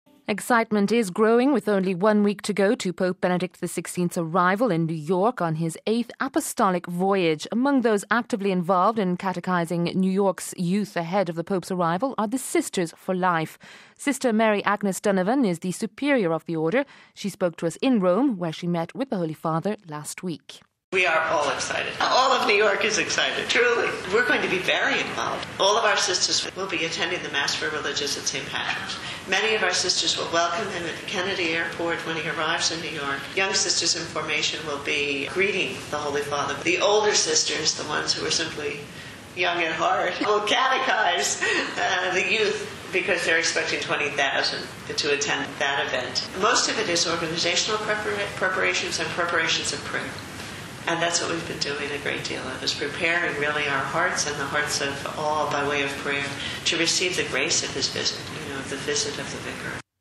She spoke to us in Rome, where she met with the Holy Father last week: RealAudio